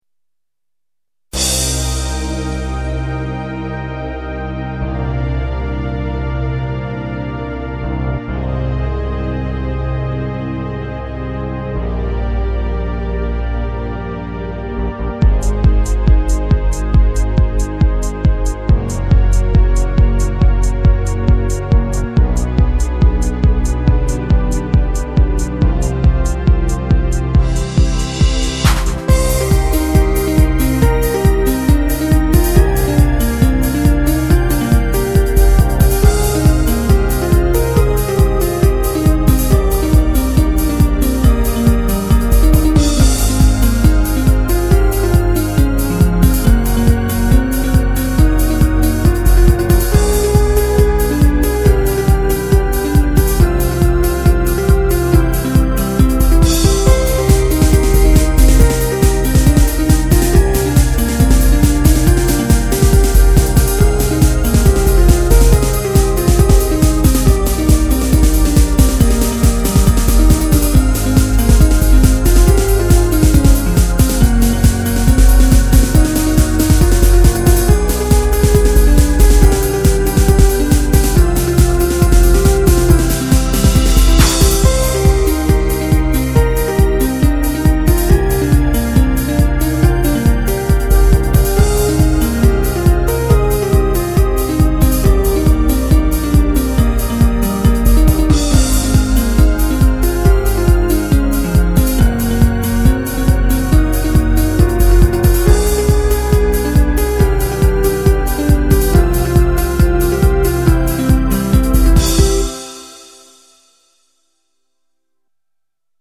・シューティングっぽいもの第2弾